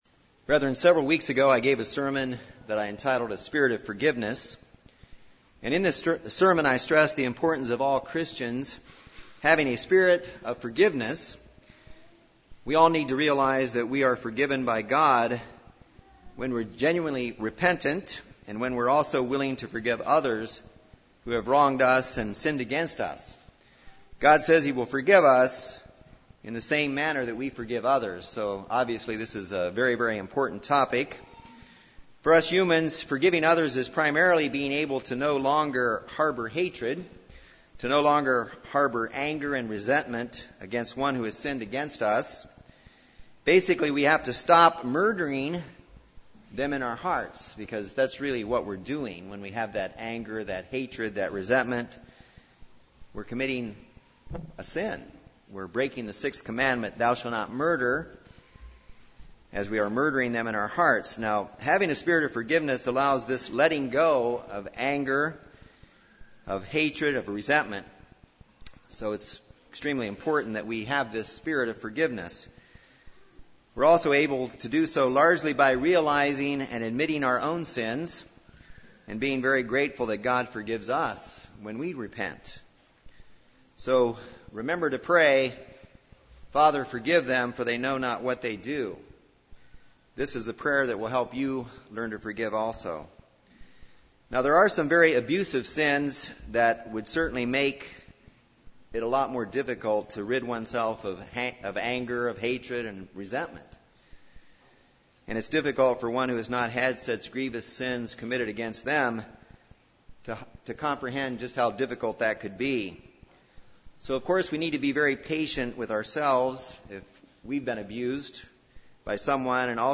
This sermon addresses what the Bible means by going to your brother and answers the questions of why, how, and when to go to your brother.